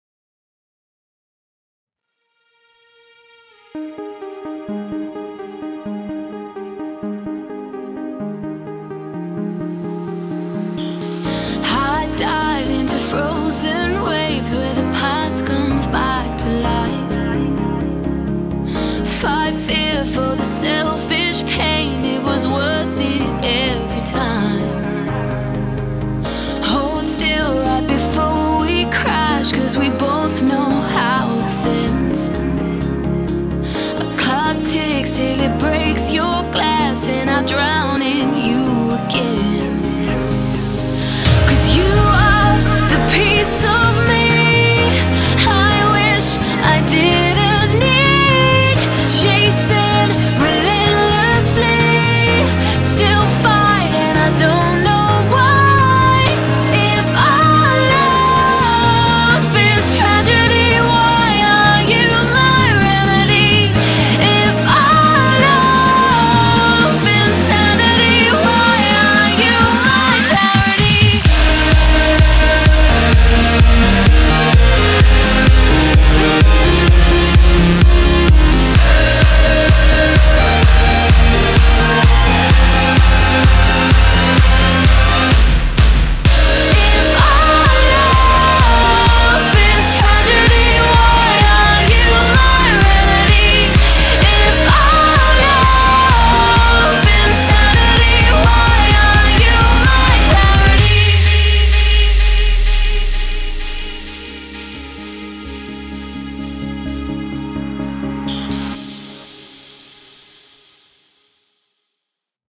BPM128
MP3 QualityMusic Cut